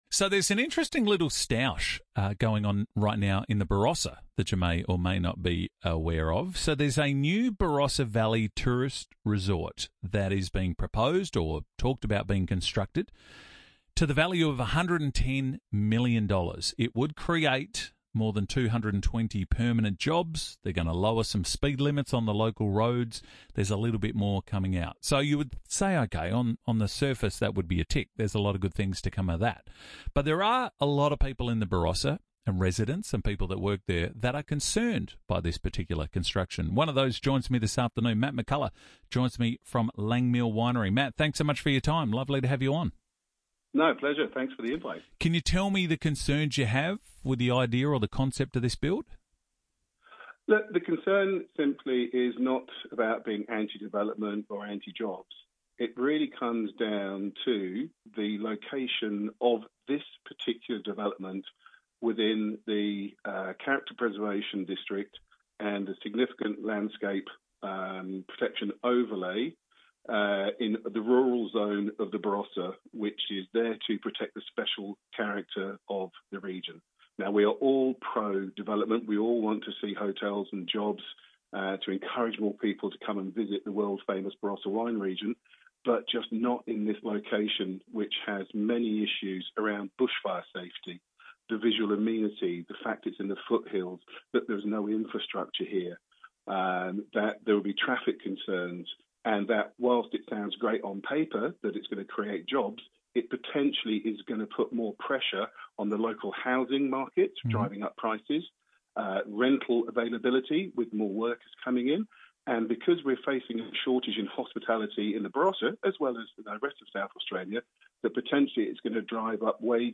You can also listen to a radio interview about the project here, which explores different perspectives and key issues raised by the proposal.